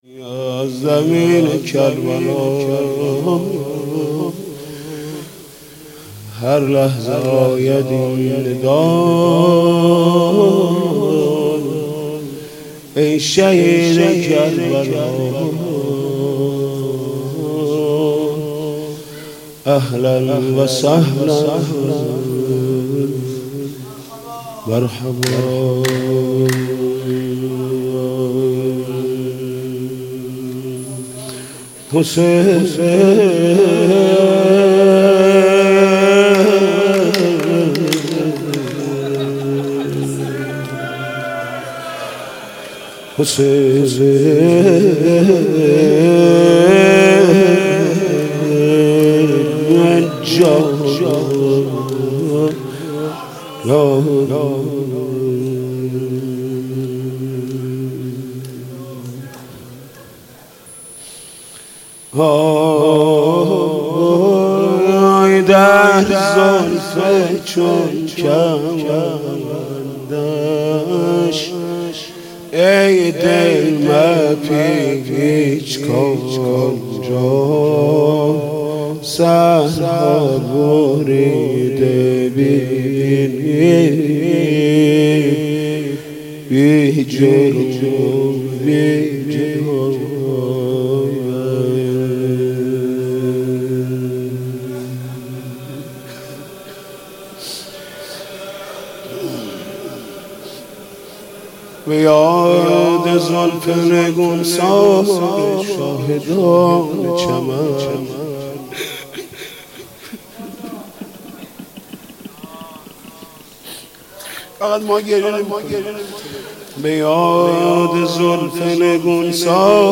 نوحه محرم